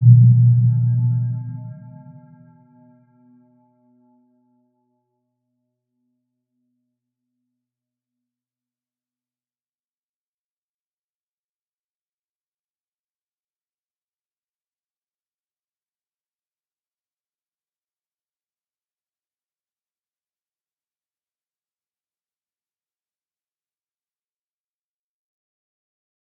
Round-Bell-B2-mf.wav